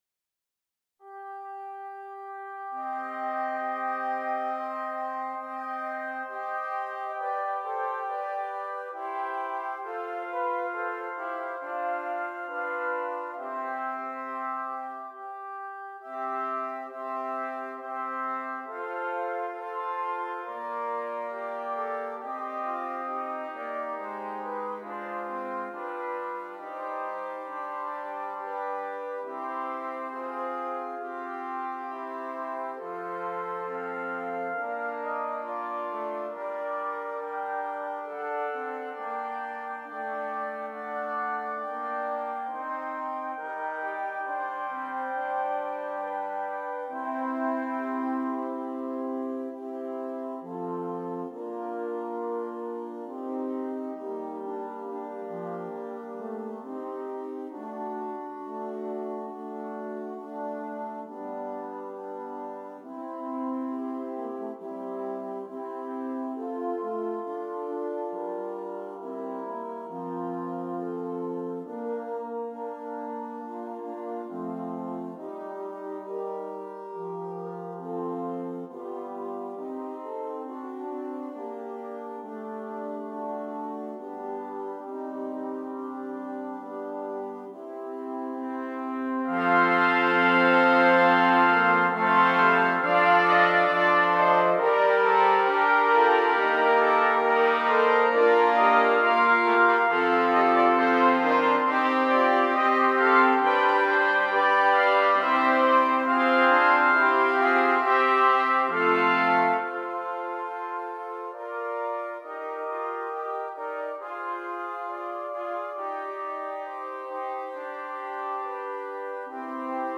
8 Trumpets
This arrangement utilizes the idea of Renaissance consorts.